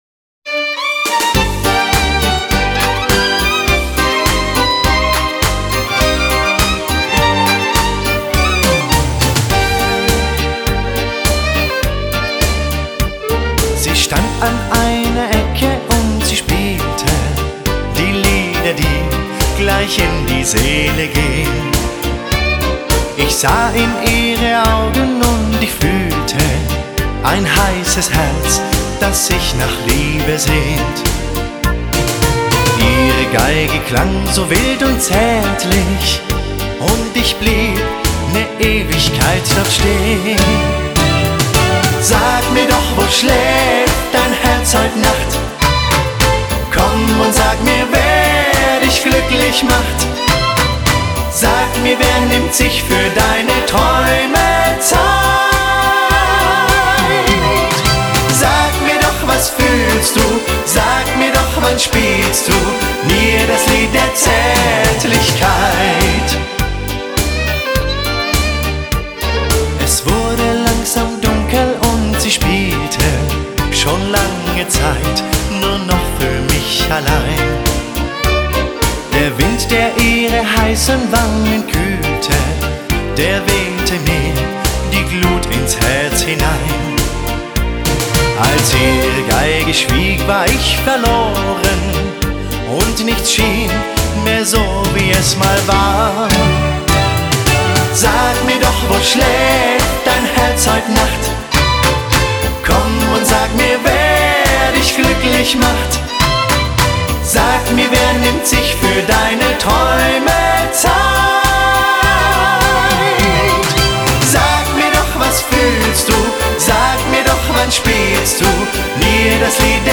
Schlager